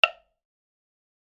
Here are one-hit clips of three percussion samples:
woodblock.mp3